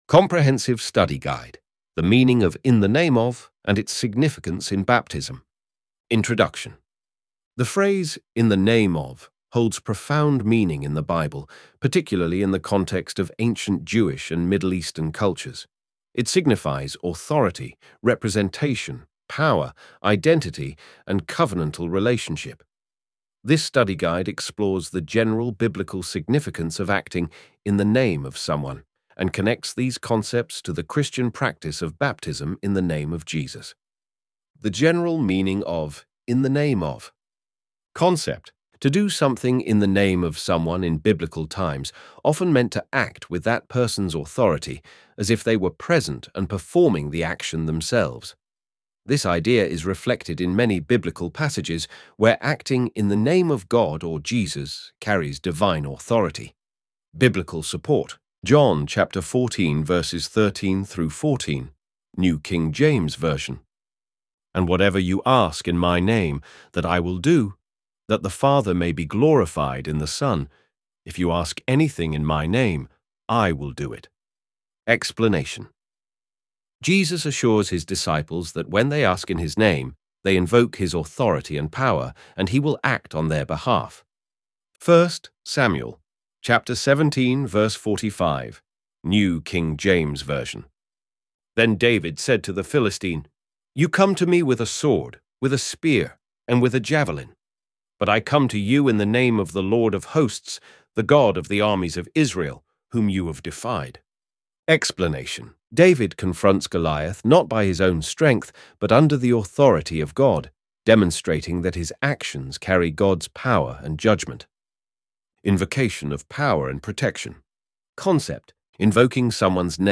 ElevenLabs_in_t-1.mp3